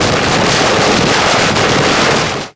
bigenemy_down.wav